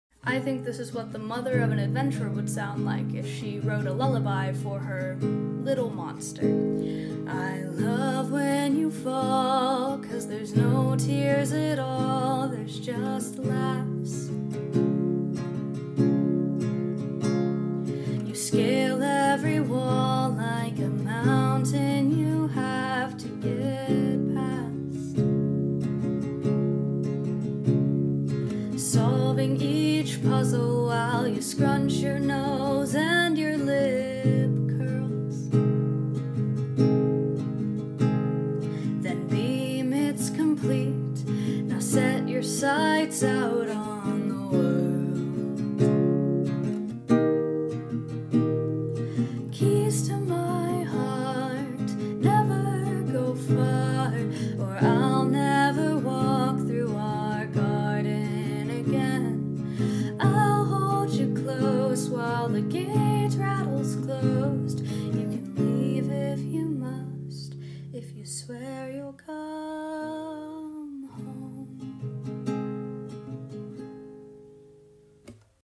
This is a lullaby she used to sing to him.